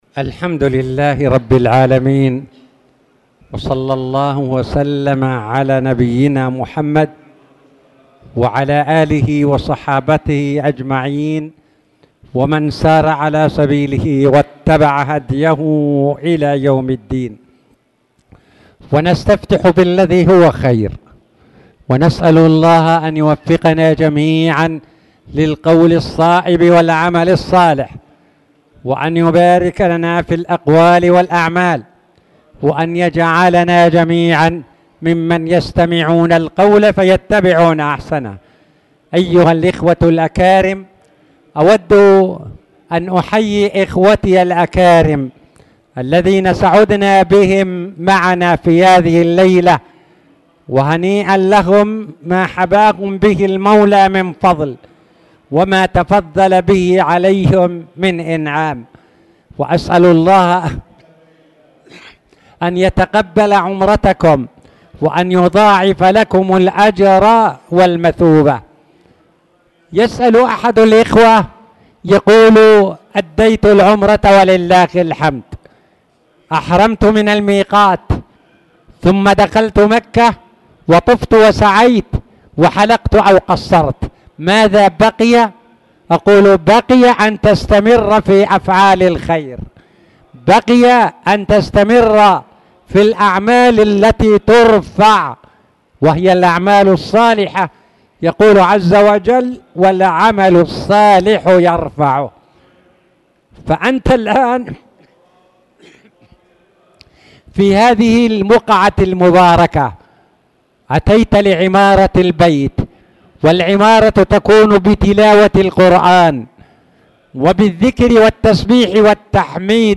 تاريخ النشر ٢٩ محرم ١٤٣٨ هـ المكان: المسجد الحرام الشيخ